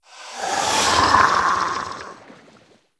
c_seasnake_bat3.wav